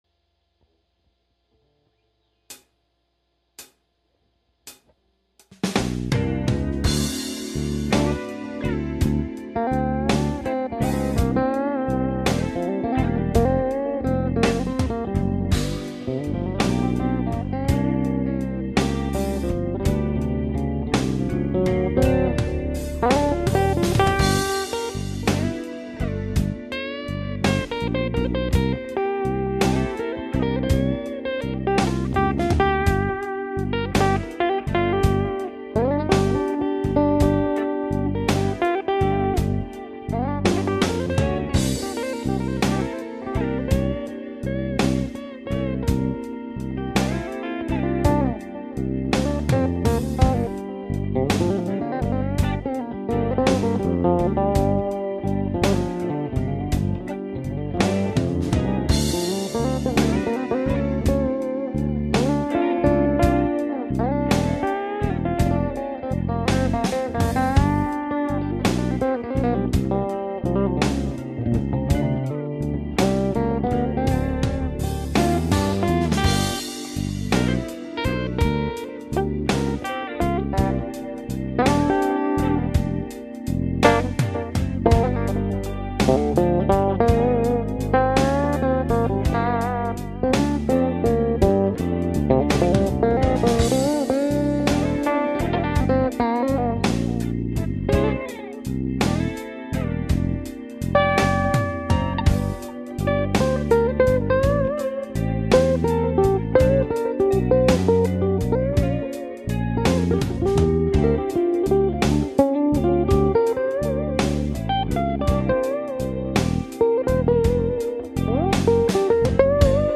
¡Qué lindo suena! Qué limpieza en esa melodía.